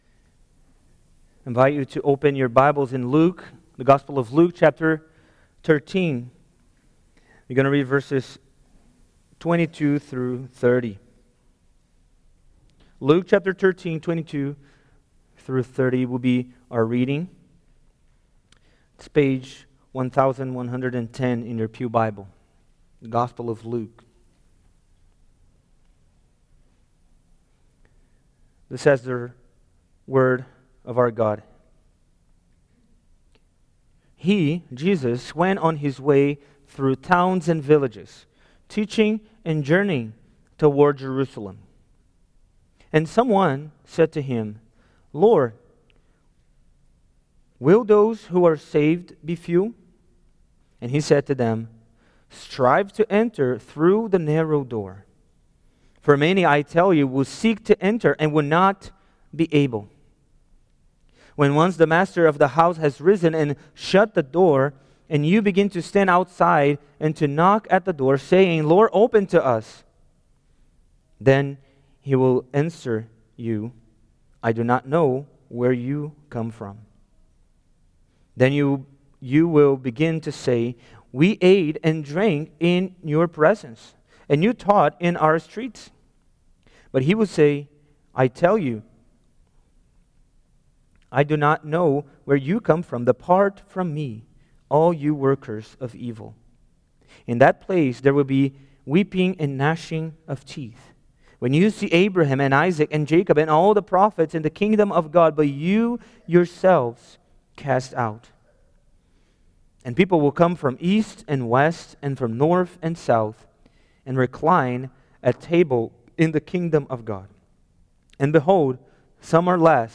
Salvation Series Various Sermons Book Luke Watch Listen Save In Luke 13:22-30, Jesus highlights the contrast between false religion and true religion.